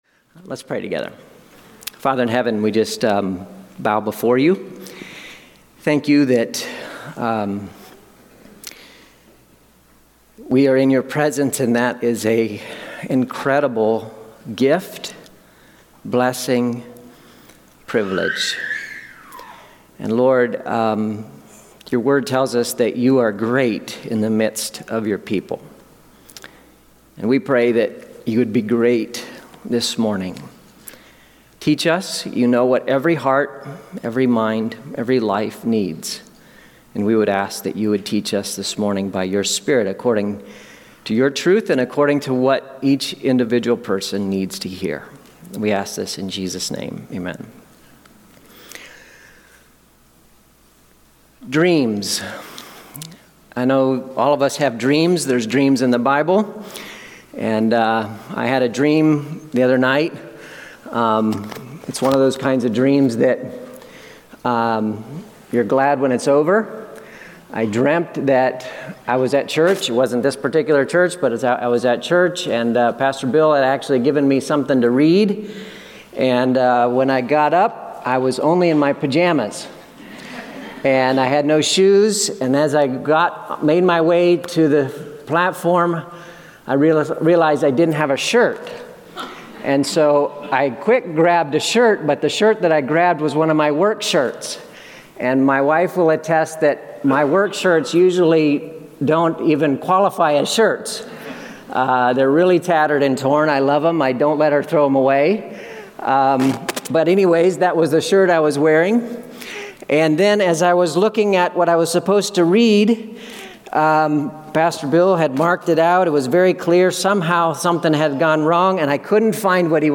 Sermons | Staunton Alliance Church